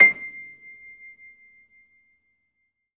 53d-pno22-C5.aif